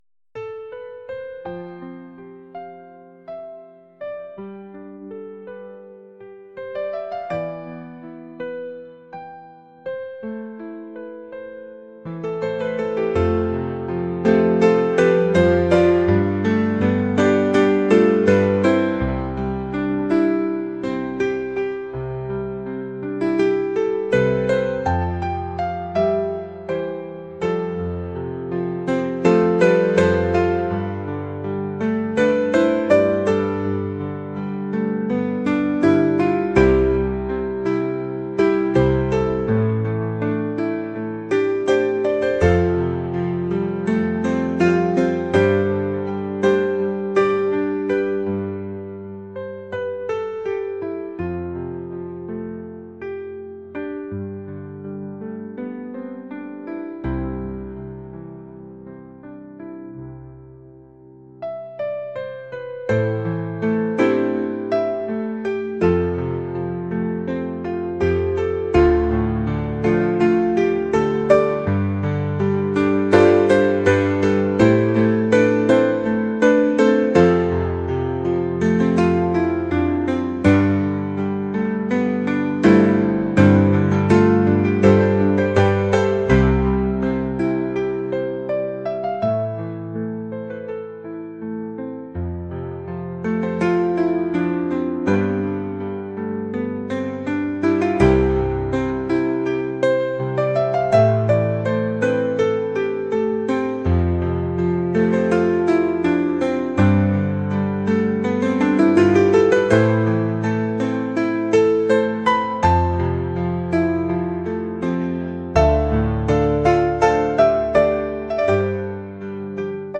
acoustic | ambient | lofi & chill beats